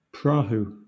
Ääntäminen
Vaihtoehtoiset kirjoitusmuodot proa Ääntäminen Southern England Tuntematon aksentti: IPA : /ˈpɹɑː.huː/ Haettu sana löytyi näillä lähdekielillä: englanti Käännöksiä ei löytynyt valitulle kohdekielelle.